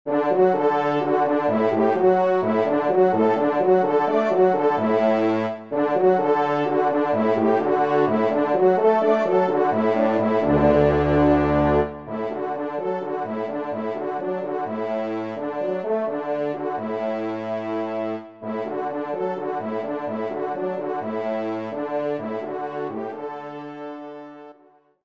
Arrangement Trompe et Piano
3e Trompe